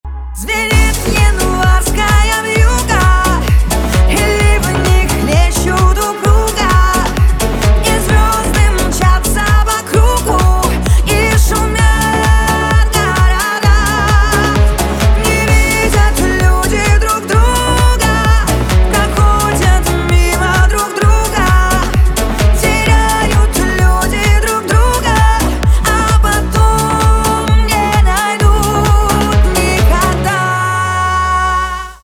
поп
cover , битовые , басы
грустные , печальные